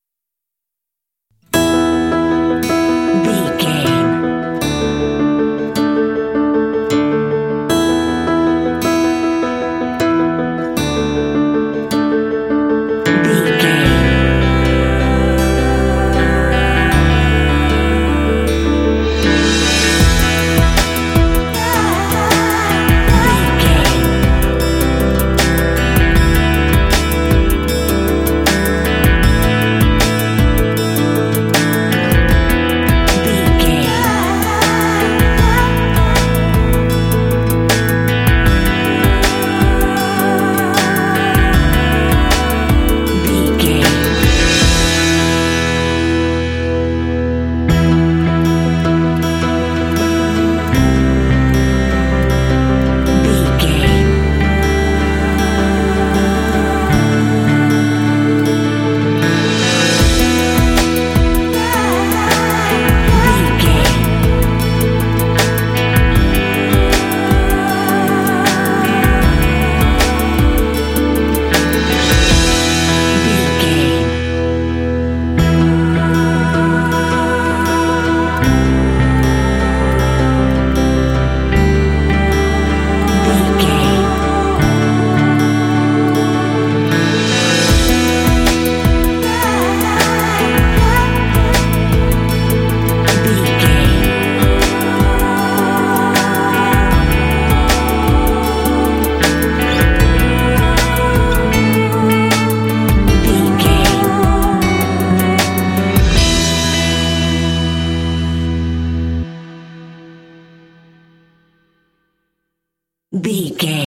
Aeolian/Minor
contemplative
melancholy
acoustic guitar
piano
bass guitar
vocals
drums